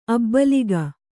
♪ abbaliga